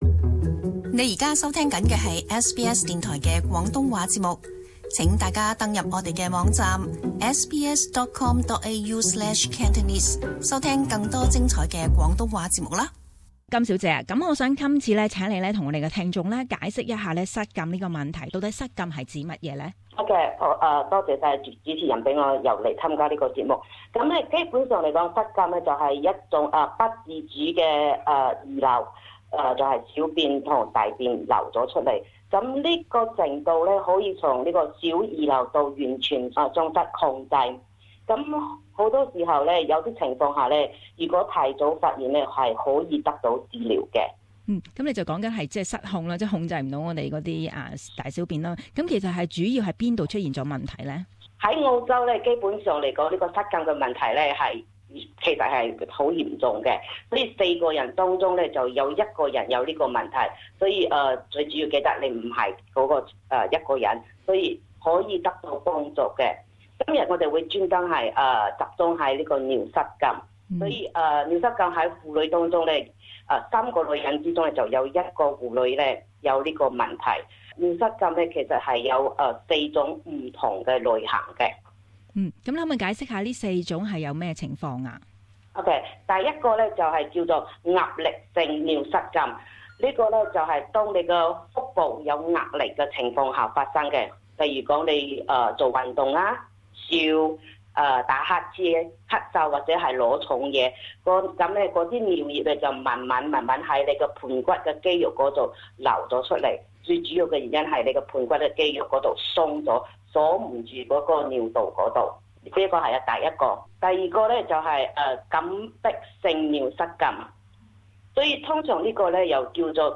Community Interview: Incontinence